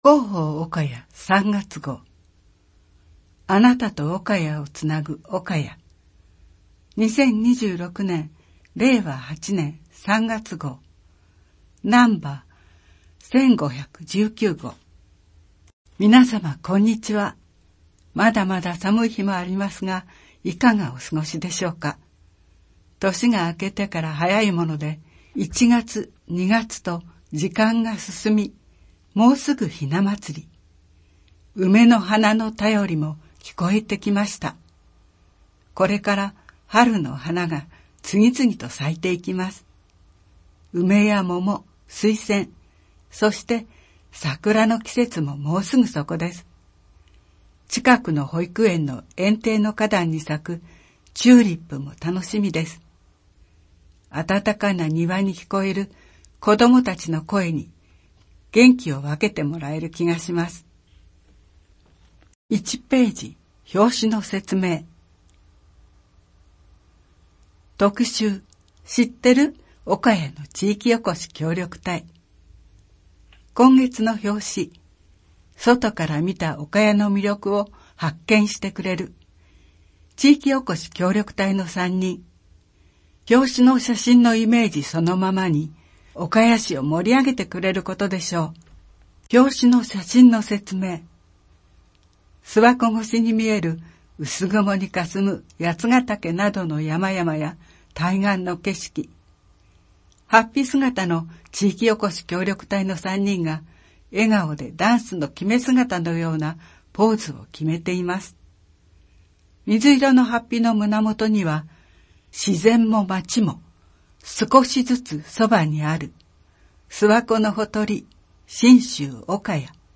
声の広報　おかや　音声版
声の広報は朗読の会まどかが担当しています。